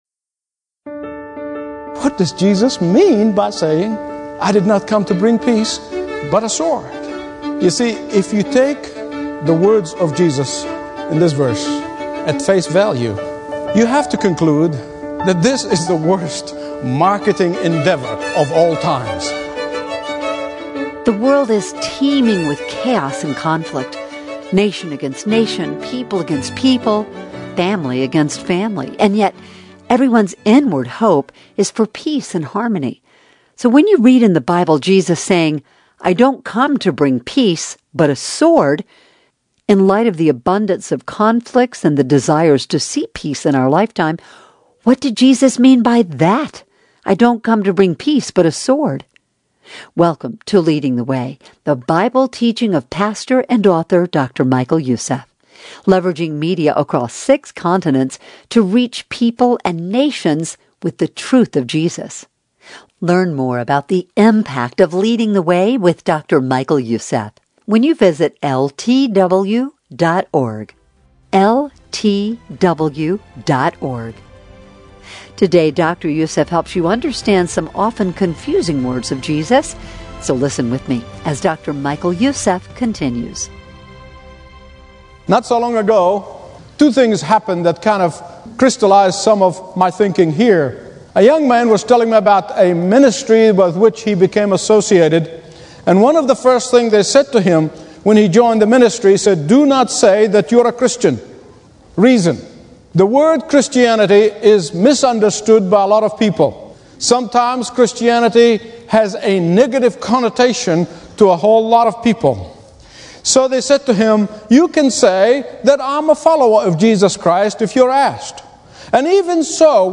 In this powerful message